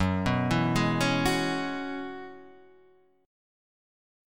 F# 9th Suspended 4th